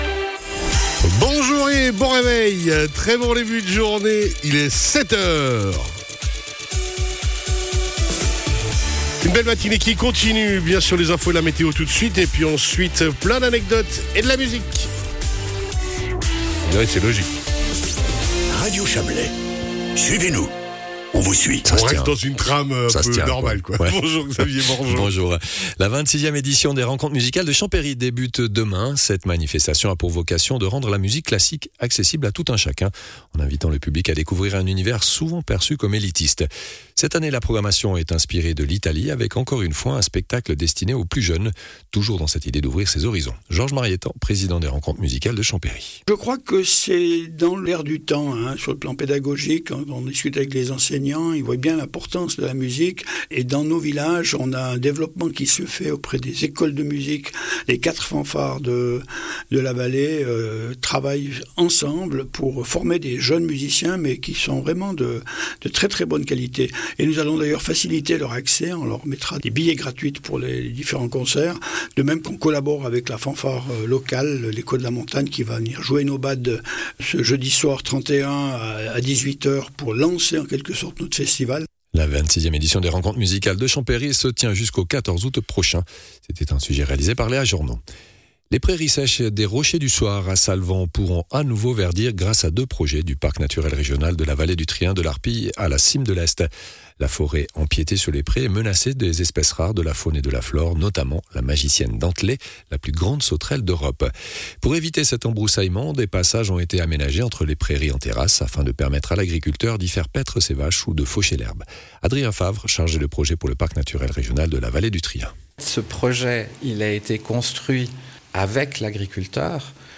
Le journal de 7h00 du 30.07.2025